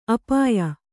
♪ apāya